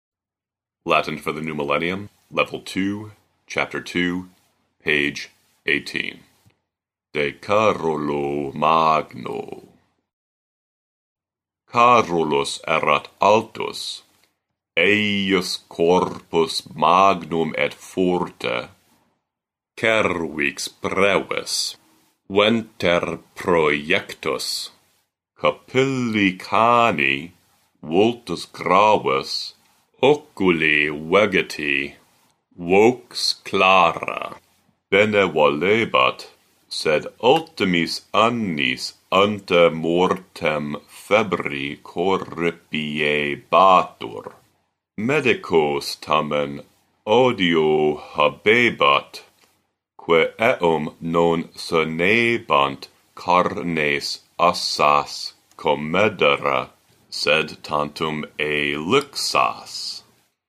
provide a professionally recorded reading in the restored classical pronunciation of Latin.